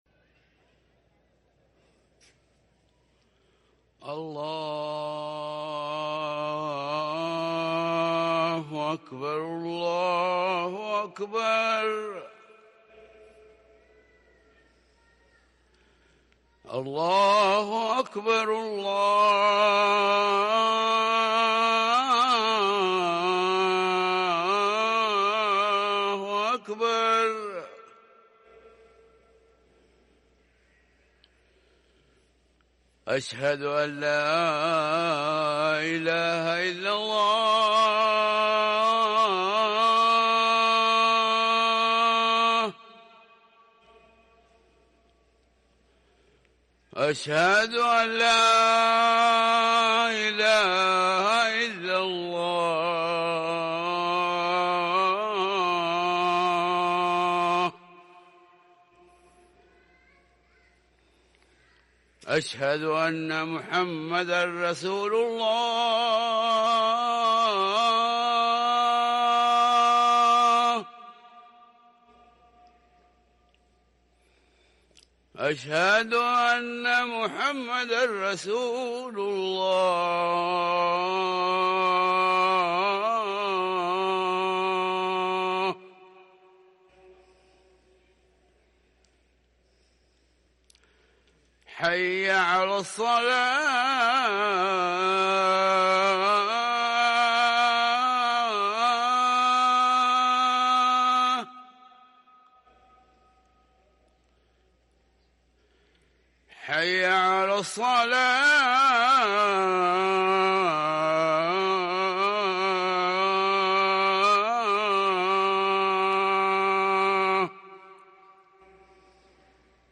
اذان الفجر
ركن الأذان